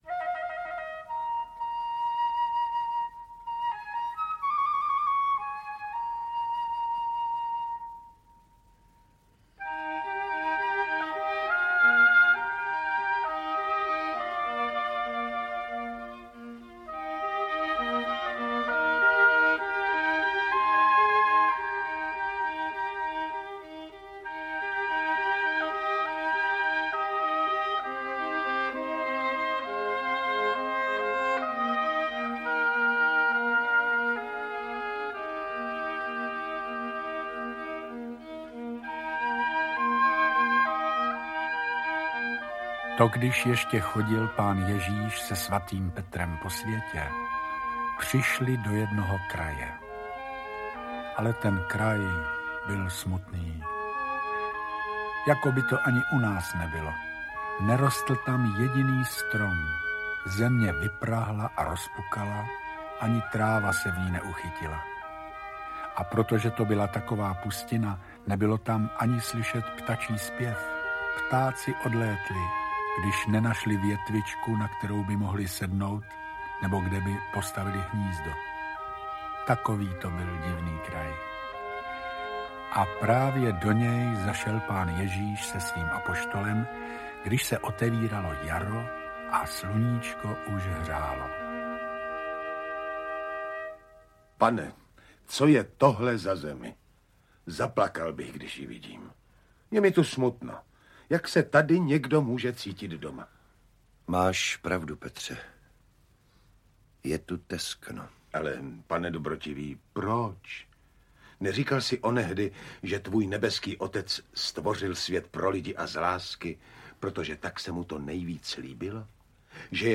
Audiokniha
stáhnout ukázku Varianty: Vyberte Audiokniha 189 Kč Další informace: Čte: Viktor Preiss, Pavel Soukup, Ivana…
Věříme, že vás zaujmou i mnohá Pánem Ježíšem (v podání Jiřího Bartošky) zmíněná podobenství, jejich smysl byl výchovný pro Petra (Alois Švehlík), ale jsou platná obecně a hlavně i v dnešní době.